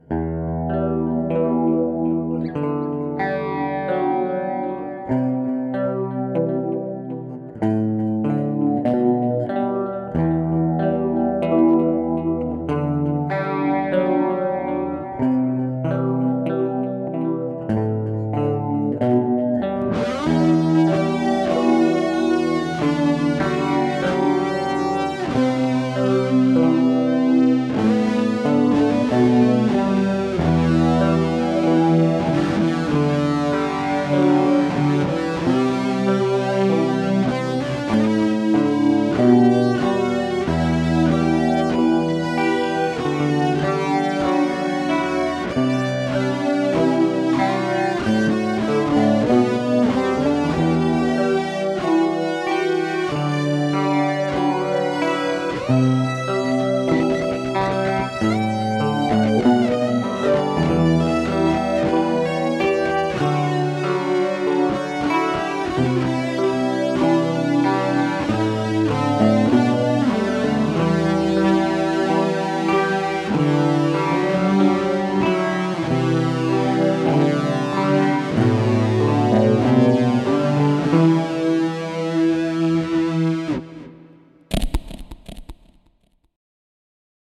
I wrote the main part on the nylon string and just loved the sound. There are two guitars here for the main part and a low solo over the top.
It was all done in one take and I can hear my hesitation after the first screw-up.